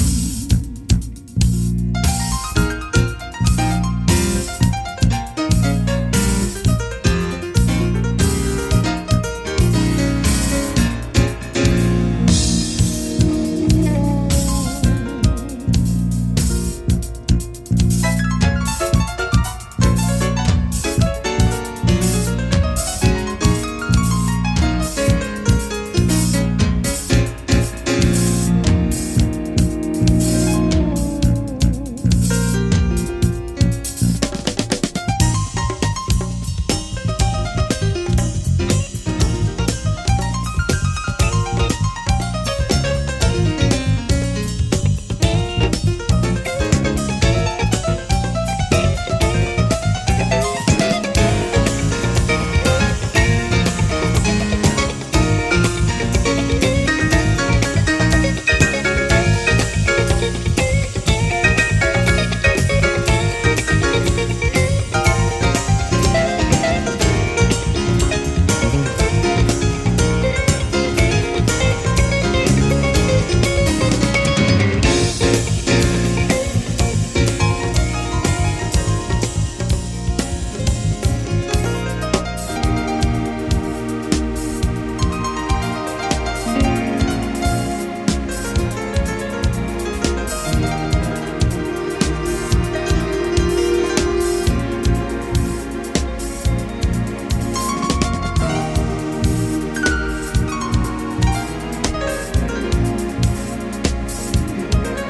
légèrement plus funky et scandé
superbe chorus de piano